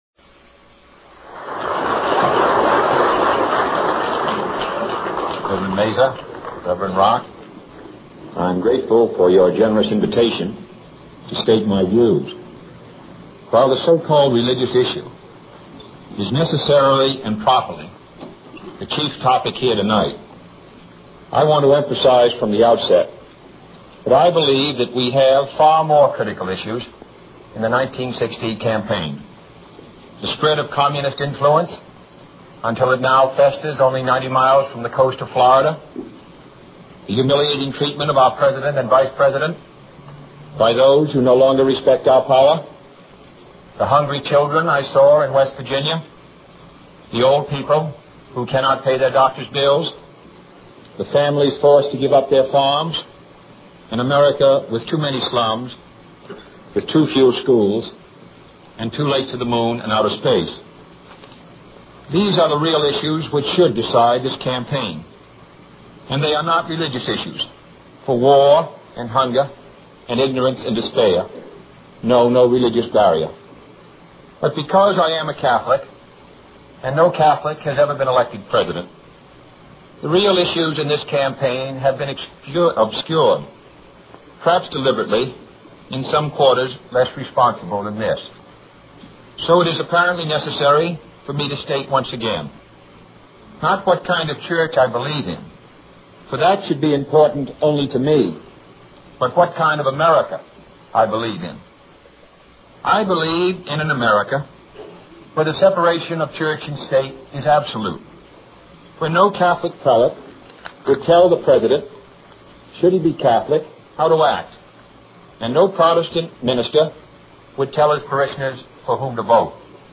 美国经典英文演讲100篇:Houston Ministerial Association Speech 听力文件下载—在线英语听力室